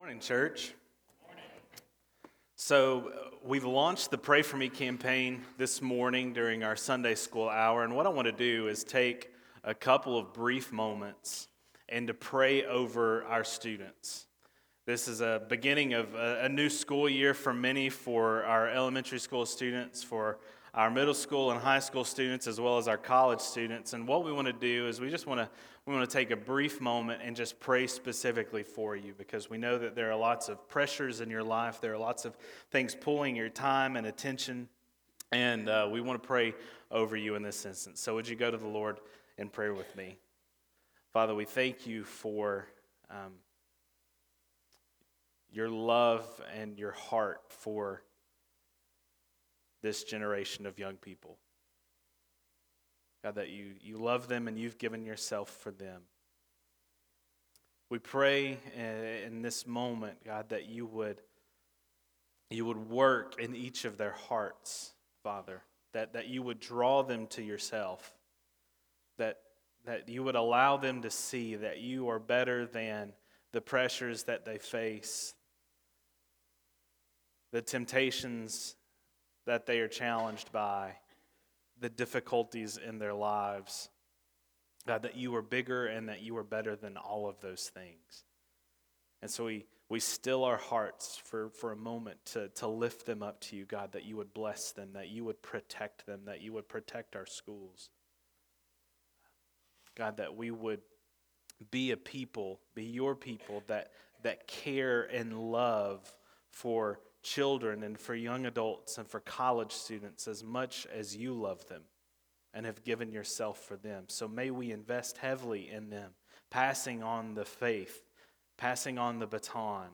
Sermons › Mark 4:35-41 – Large and In Charge